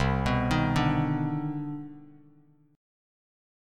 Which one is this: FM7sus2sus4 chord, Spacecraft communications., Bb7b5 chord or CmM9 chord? CmM9 chord